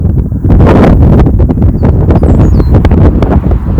Saffron-cowled Blackbird (Xanthopsar flavus)
Country: Argentina
Province / Department: Entre Ríos
Condition: Wild
Certainty: Photographed, Recorded vocal